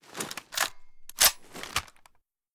ru556_drawalt.ogg